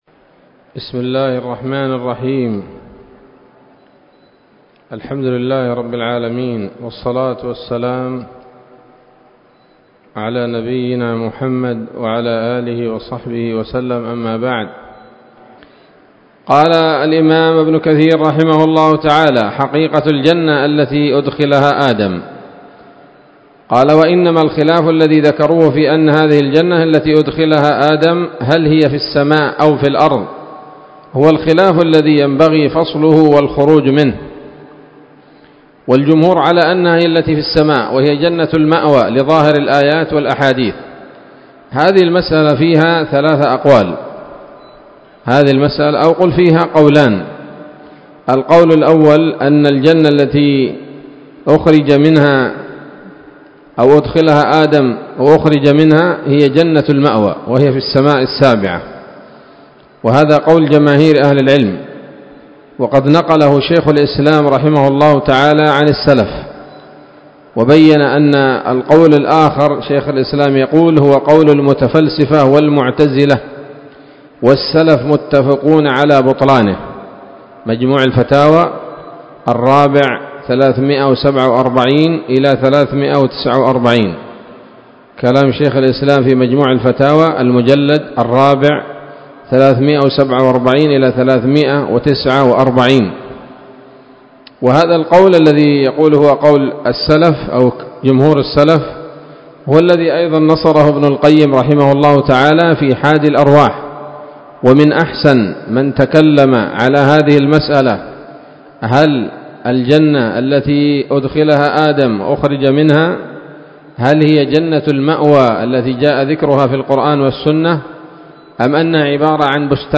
الدرس الخامس من قصص الأنبياء لابن كثير رحمه الله تعالى